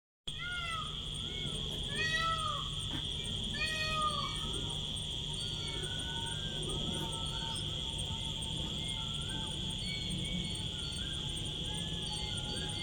Download Wildlife sound effect for free.
Wildlife